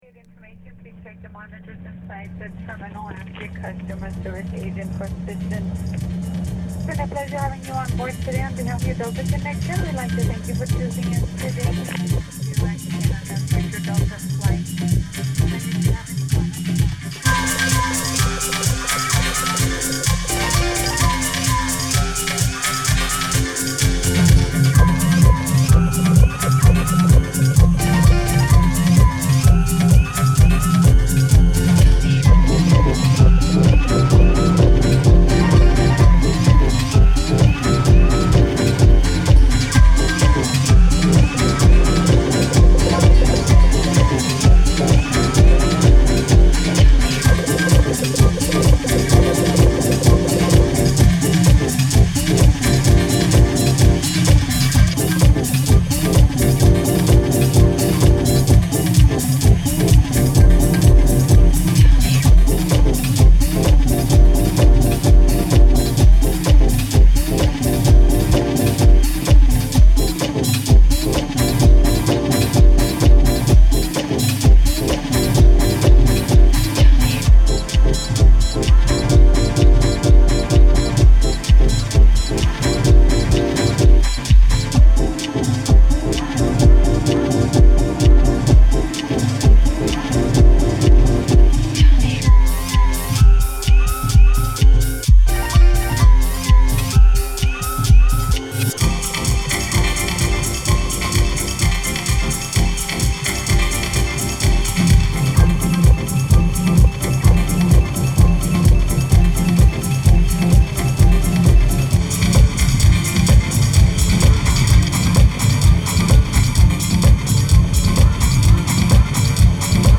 ウォーミーでエキゾチックなラウンジー・コラージュのA3
ヒップホップ的ニュアンスのメロウ・ジャジーなビートダウンB2
オーガニックでウォーミーなサウンド、ロウなビート/グルーヴ、トリッピーなコラージュ感が融合したグッド・トラックを収録！